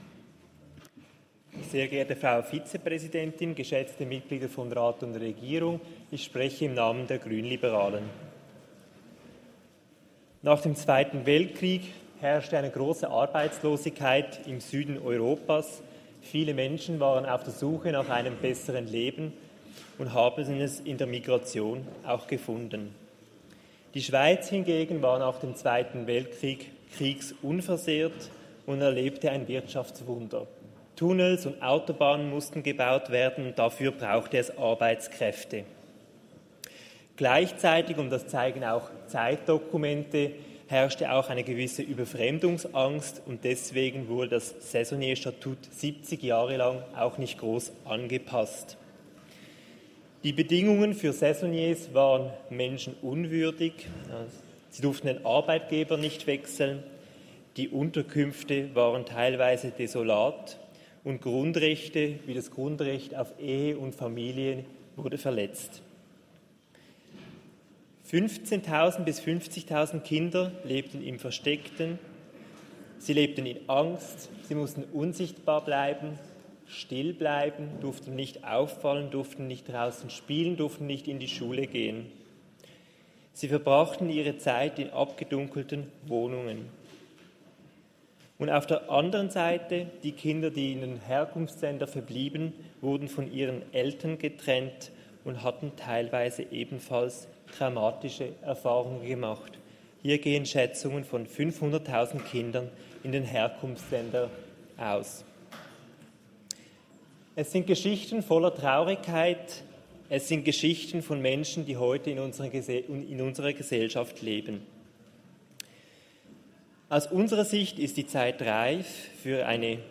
Session des Kantonsrates vom 29. April bis 2. Mai 2024, Aufräumsession
1.5.2024Wortmeldung